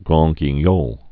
(gräɴ gē-nyôl)